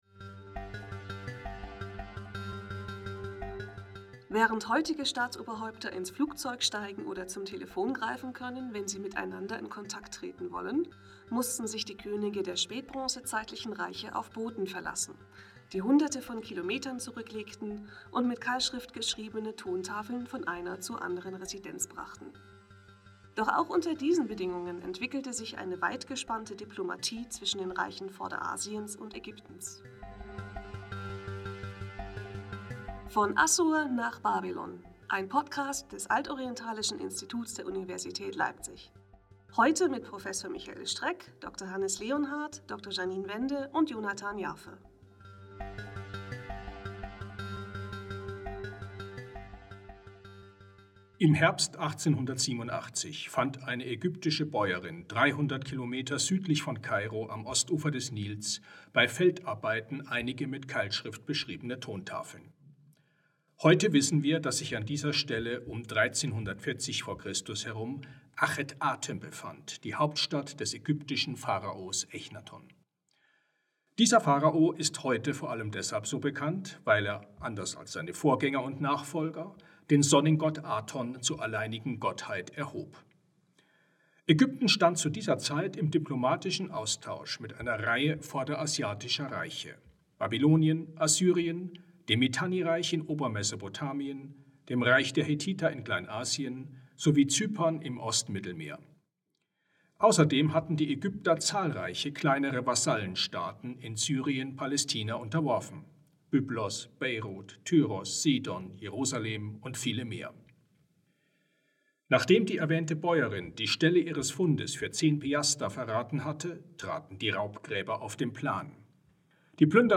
Soundeffekte (von Pixabay)